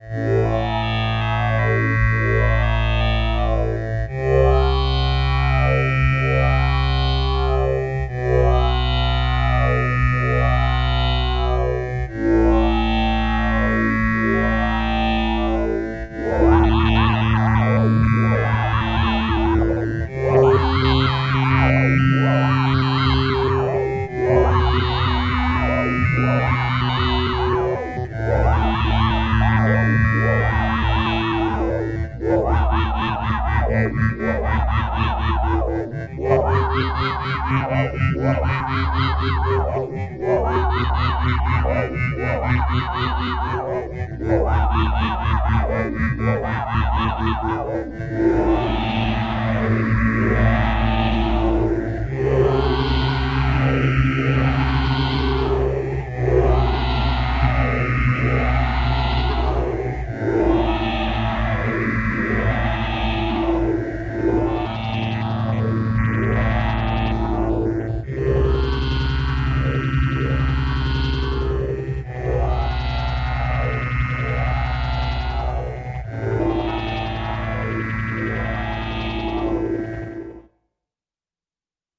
From classic analog warmth to intricate digital textures, wavetables become the building blocks for your sonic creations.
• Synthgirl - Wavetable Synth
synthgirl-record.mp3